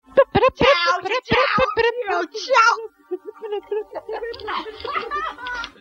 attempts the cosmic title tune while
wades in with the in-game theme